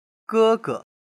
ge1ge.mp3